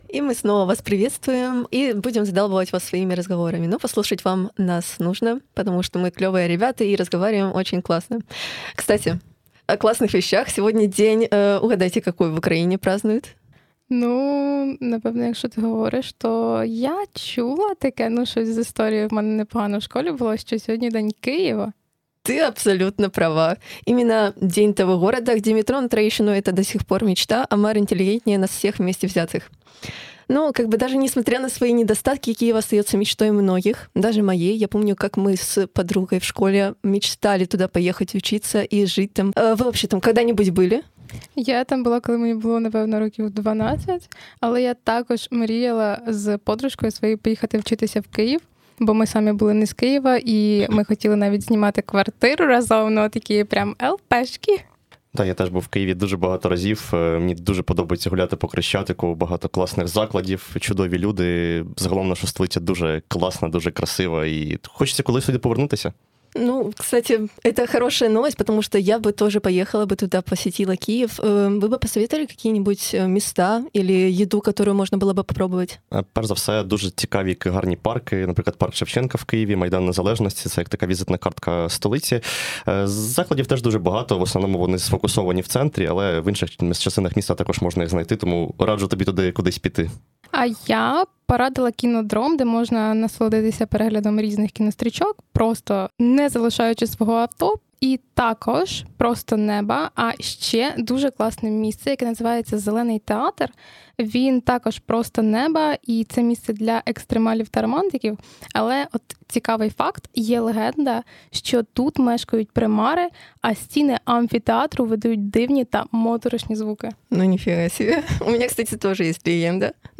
W niedzielnej audycji Szto tam omówiliśmy z kolegami nieco więcej pięknych zakątków miasta, które można odwiedzić podczas podróży w Kijów.